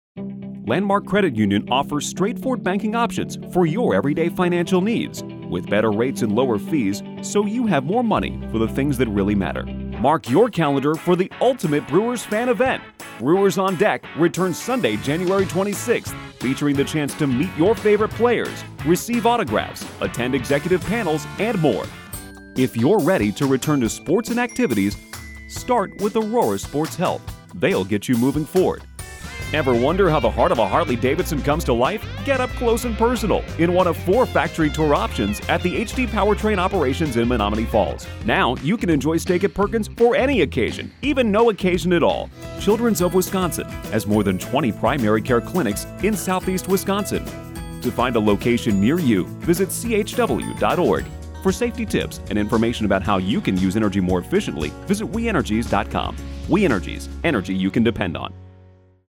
Voiceover
I deliver quality narrations and voiceovers for local clients, and provide complete fulfillment in a professional home studio setup, with editing and delivery capabilities in Adobe Audition.